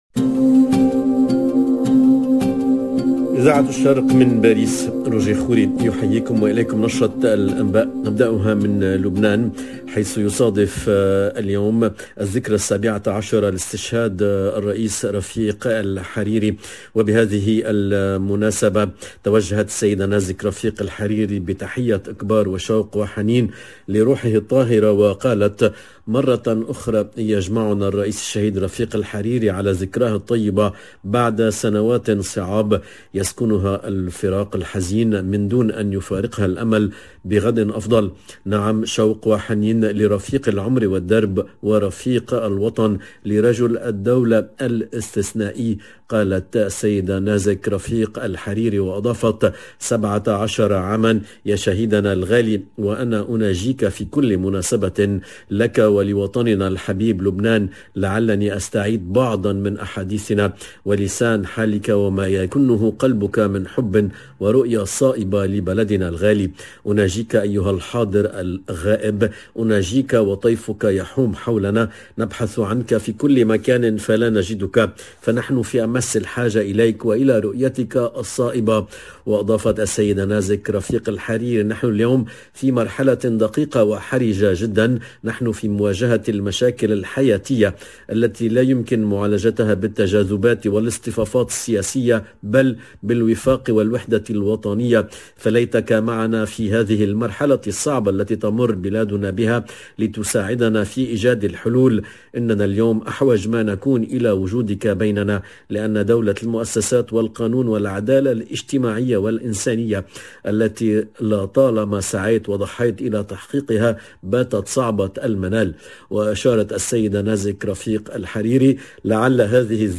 LE JOURNAL DE MIDI 30 EN LANGUE ARABE DU 14/02/22